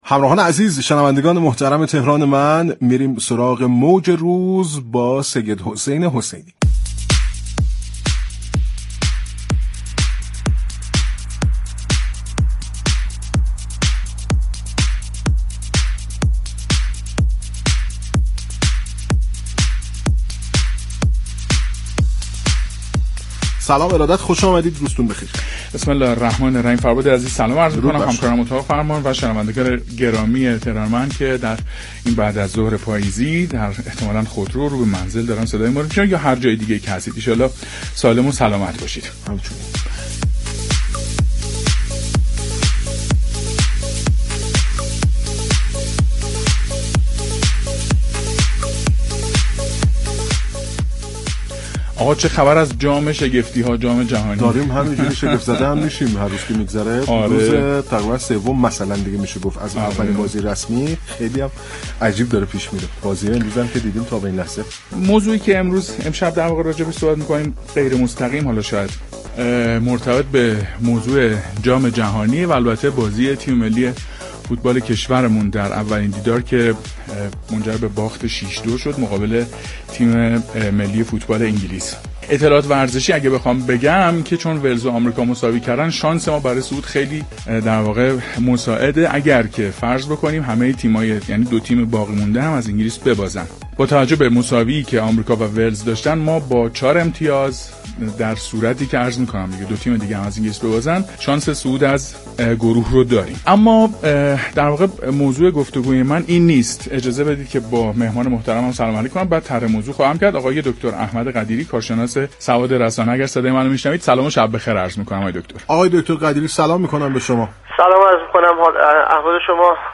كارشناس رسانه در گفت و گو با "تهران من" رادیو تهران درخصوص واكنش‌های سیاسی در فضای مجازی